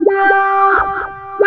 VOX FX 5  -R.wav